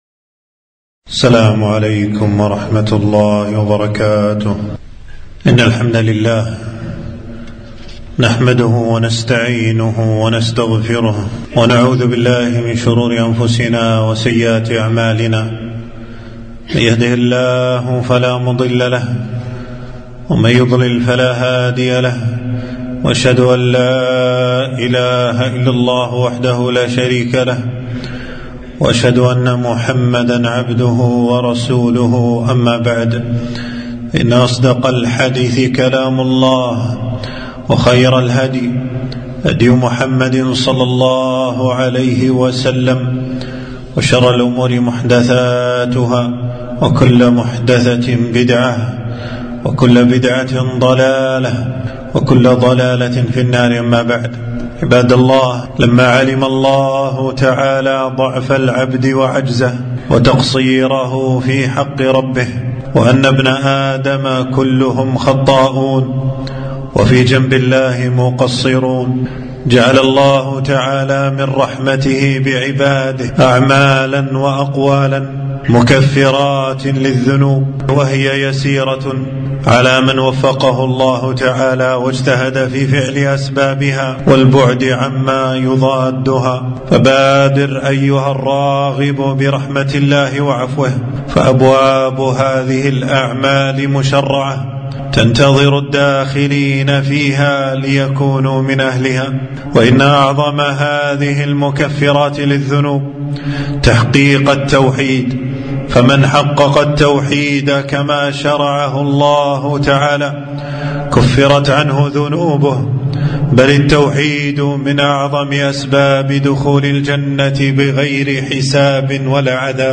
خطبة - أعمال وأقوال بها يكفر الله الذنوب